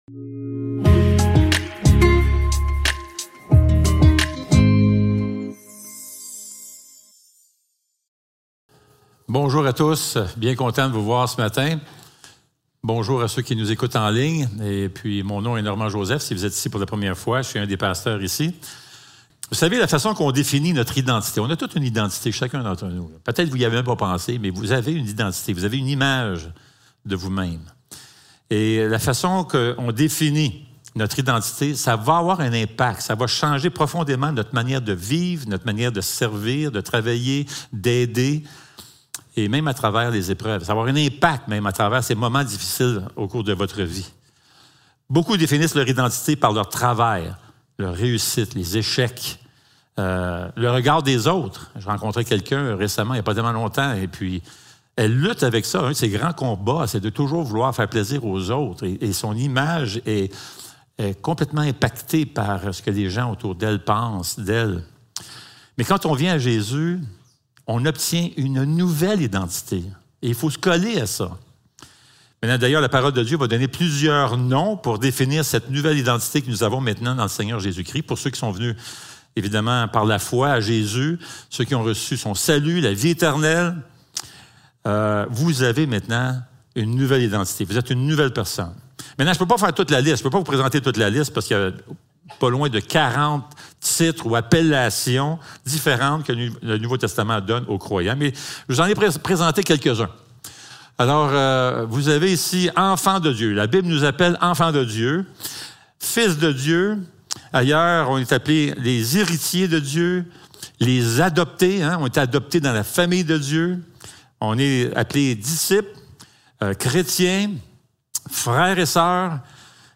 2 Corinthiens 4 Service Type: Célébration dimanche matin Description